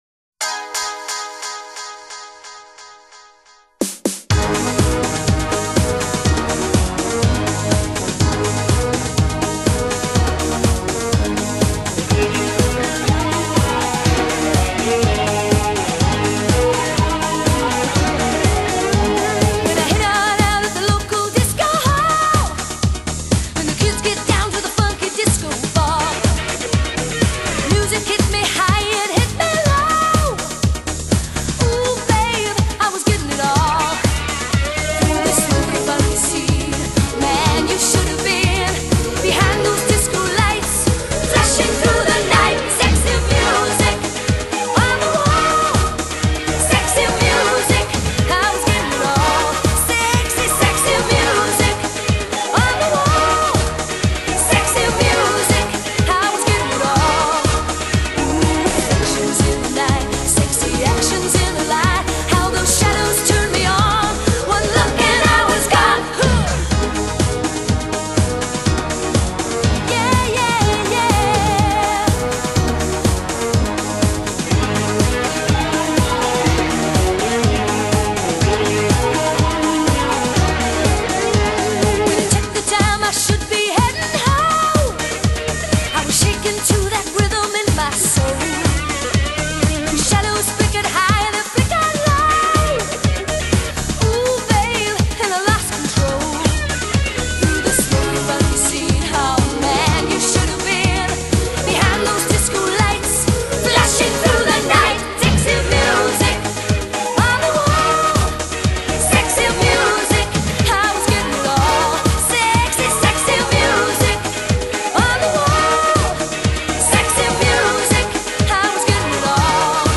Disco,Pop